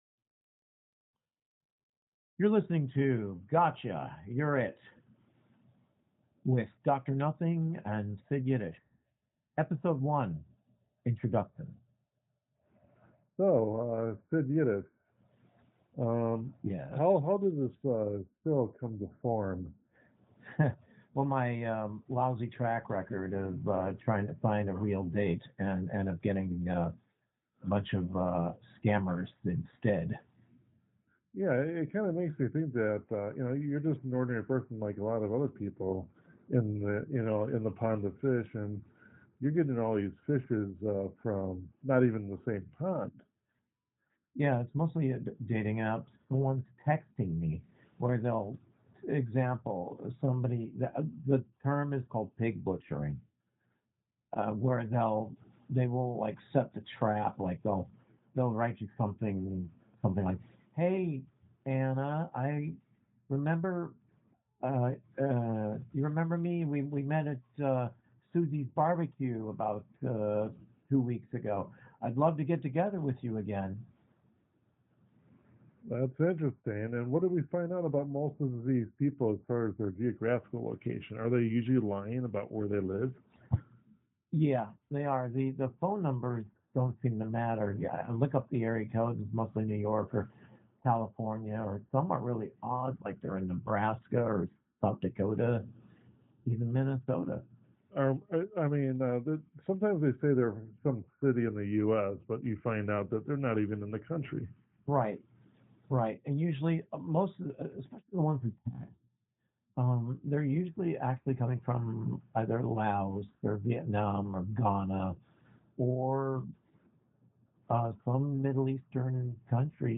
Episode I: Introduction, an interview